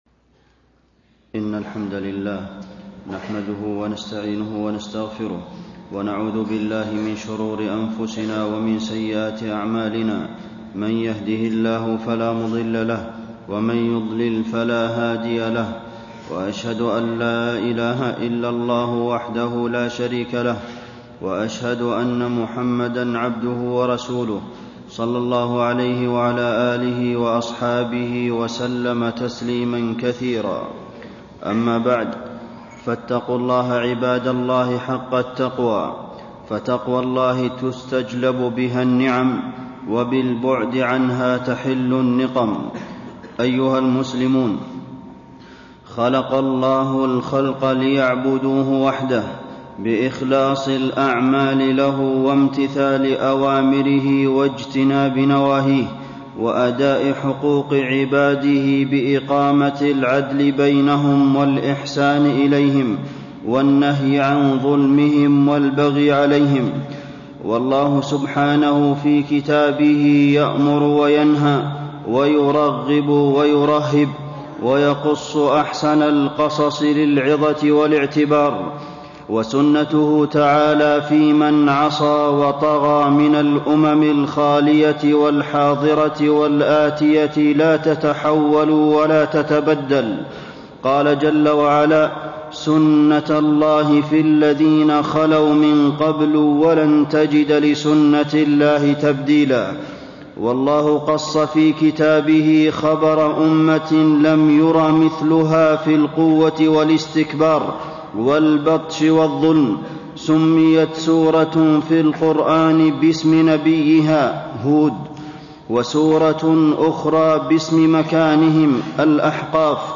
تاريخ النشر ٢٠ شوال ١٤٣٣ هـ المكان: المسجد النبوي الشيخ: فضيلة الشيخ د. عبدالمحسن بن محمد القاسم فضيلة الشيخ د. عبدالمحسن بن محمد القاسم قصة عاد في القرآن والسنة